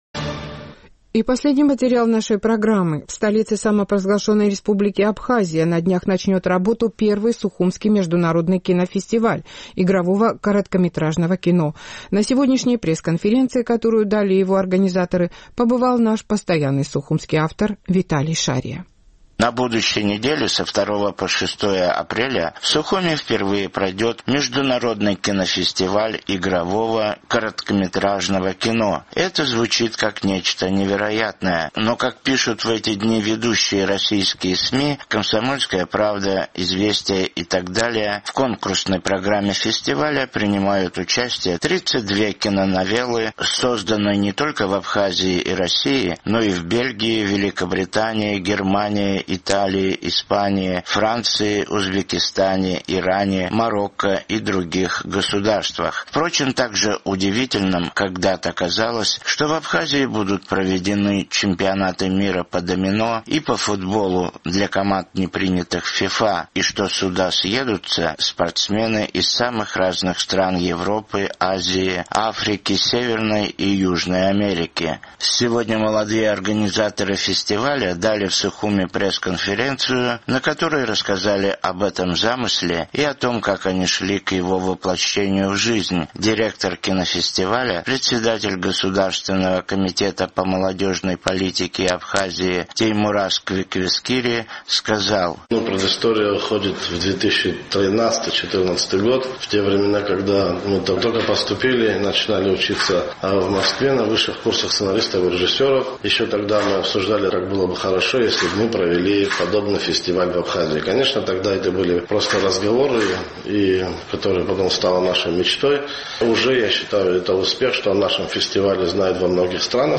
Сегодня молодые организаторы фестиваля дали в Сухуме пресс-конференцию, на которой рассказали об этом своем замысле и о том, как они шли к его воплощению в жизнь.